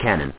w2_cannon.mp3